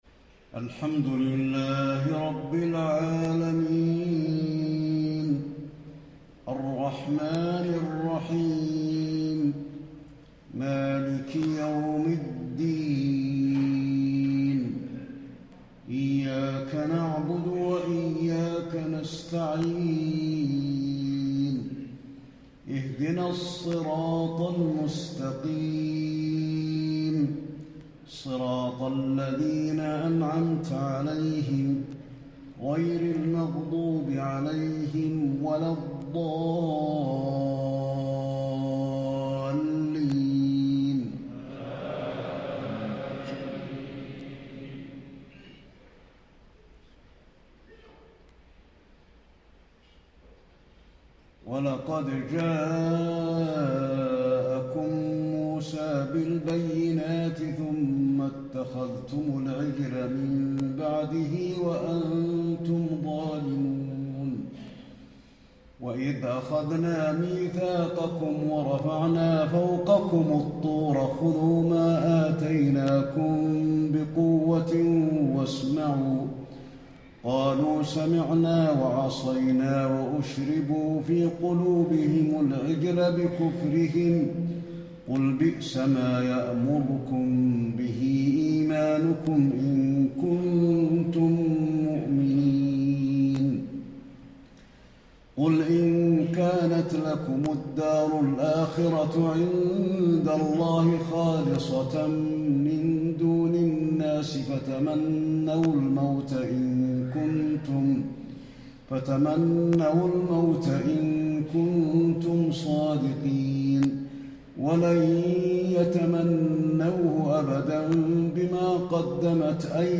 تهجد ليلة 21 رمضان 1435هـ من سورة البقرة (92-141) Tahajjud 21 st night Ramadan 1435H from Surah Al-Baqara > تراويح الحرم النبوي عام 1435 🕌 > التراويح - تلاوات الحرمين